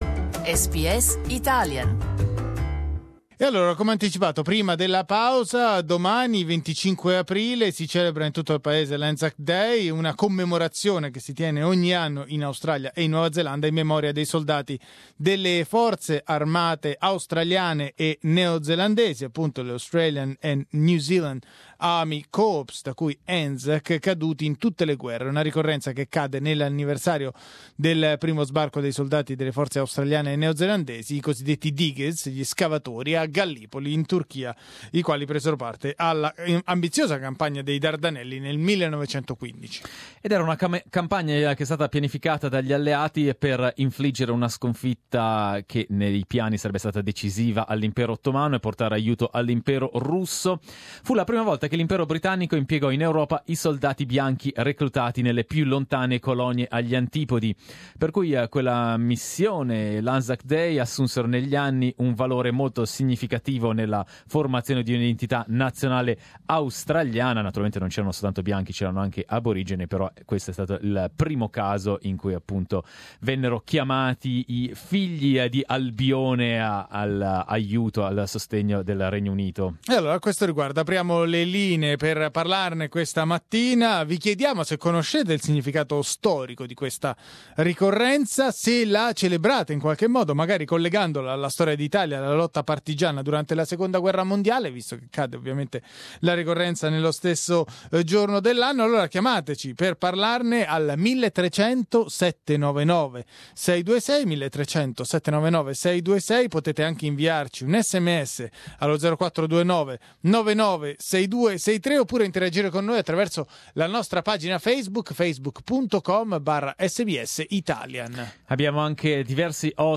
Ahead of Anzac Day we hosted a debate